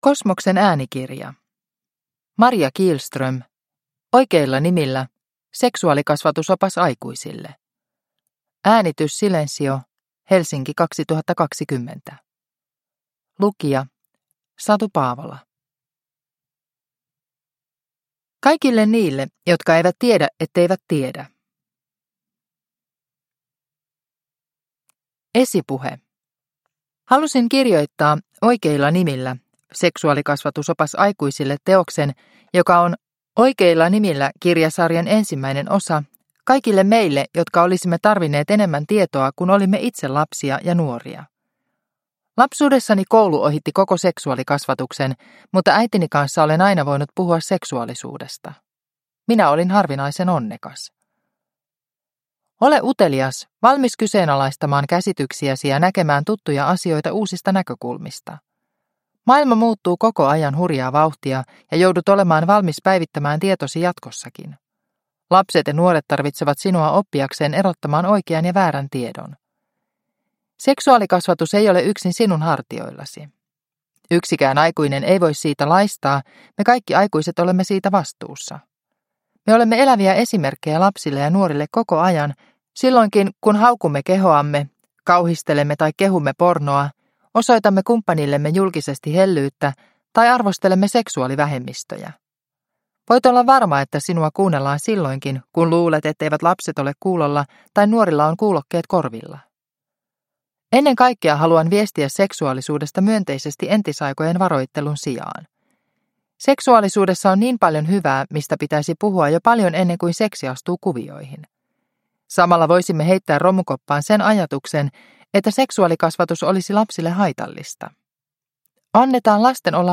Oikeilla nimillä – Ljudbok – Laddas ner